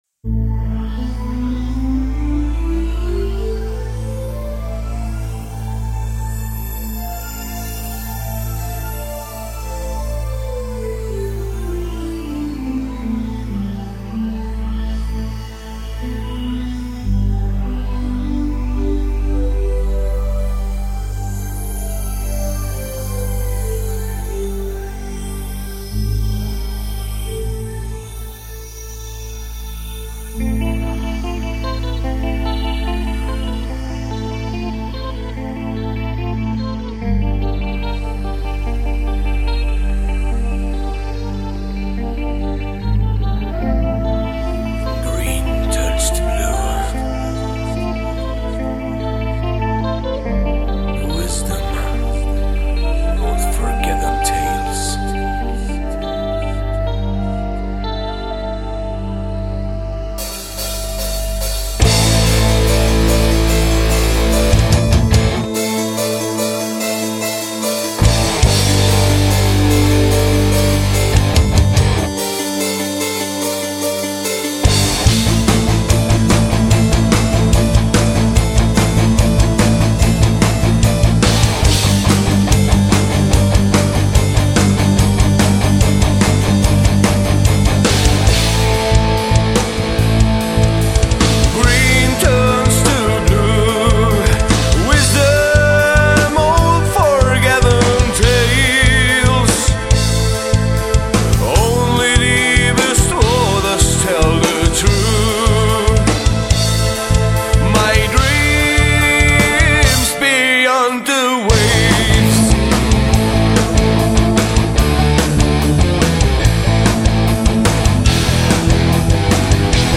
Mediabase Studio Geretsried in November 2007
Female Vocals
Flutes